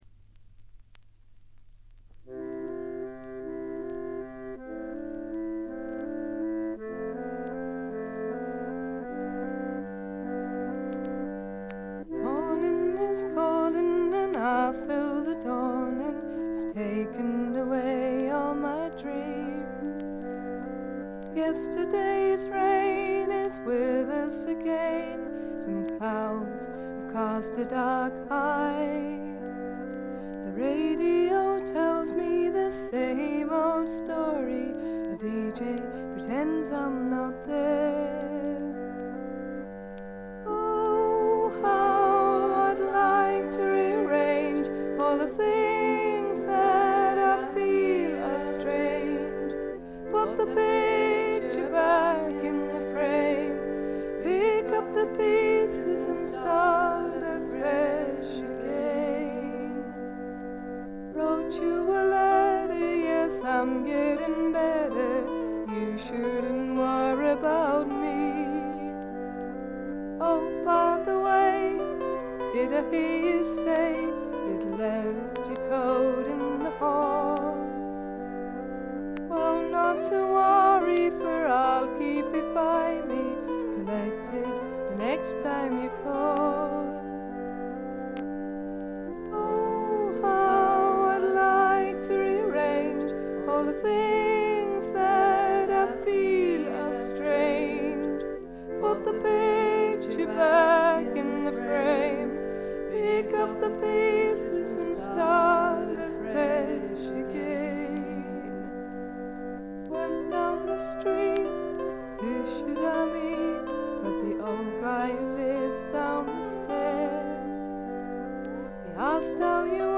軽微なバックグラウンドノイズ。
試聴曲は現品からの取り込み音源です。
Vocals, Harmonium, Electric Guitar